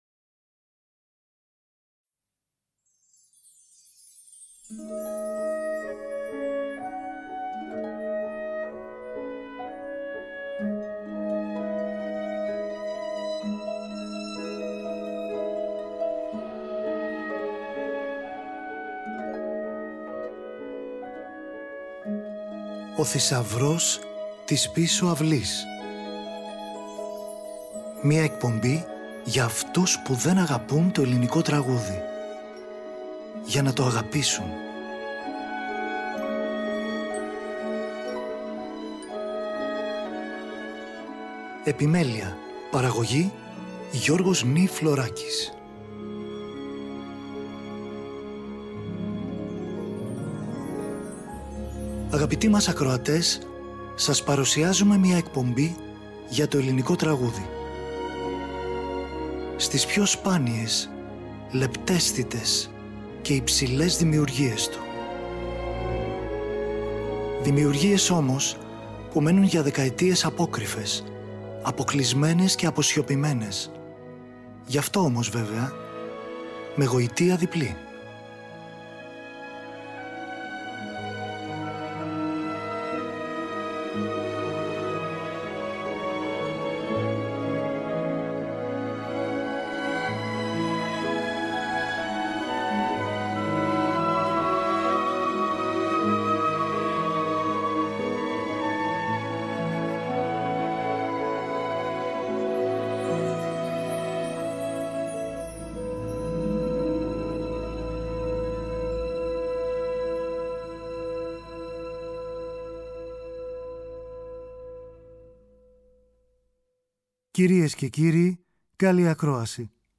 Συμφωνικό Ποίημα